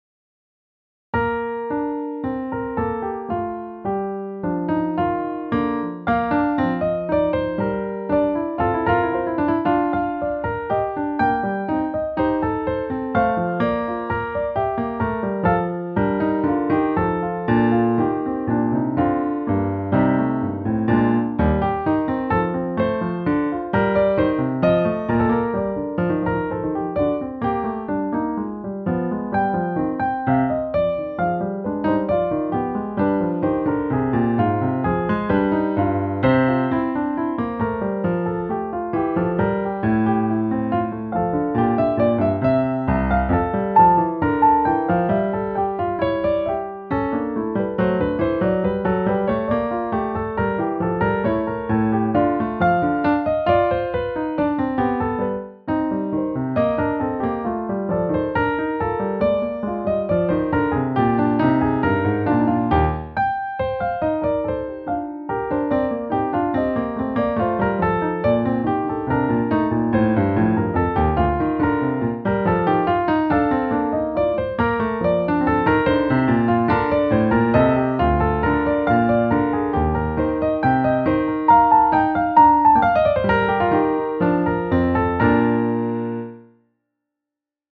久しぶりにバッハを弾いてみた。
録音を聴いて気づいたが，後半で甚だしく速くなっている。